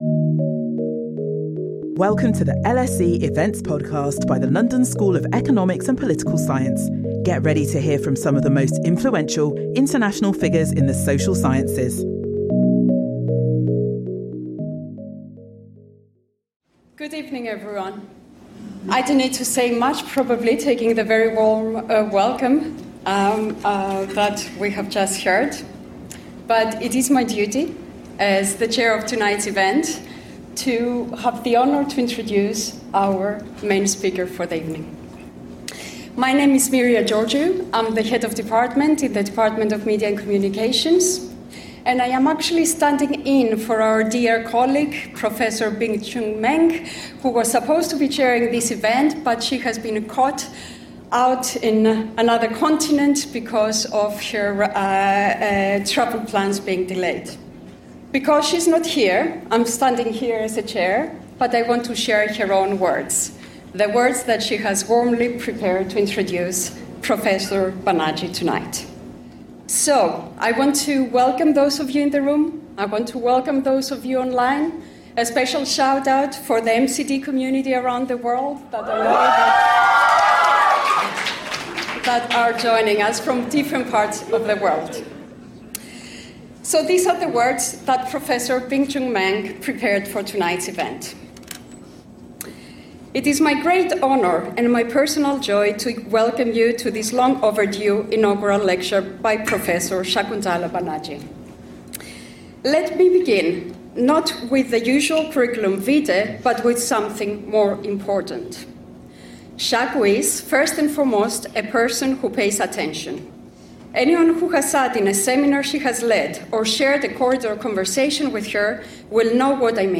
inaugural lecture